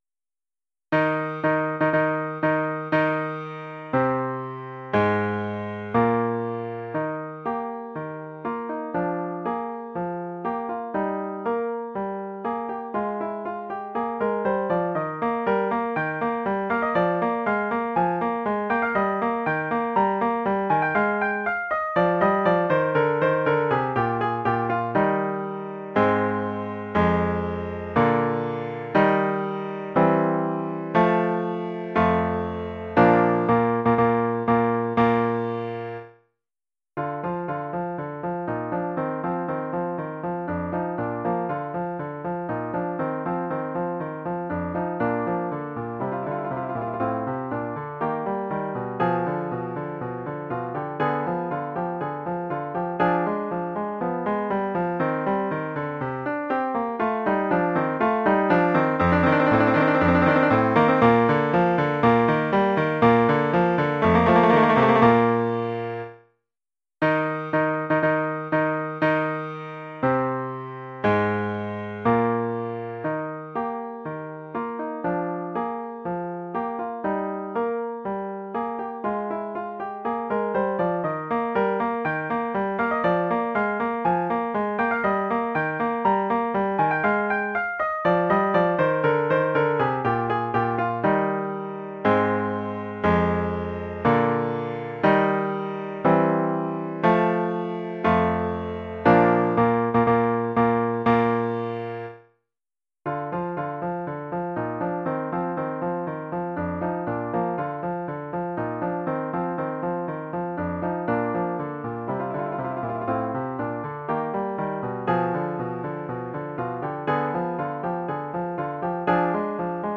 Instrumentation : Piano
Oeuvre pour piano solo.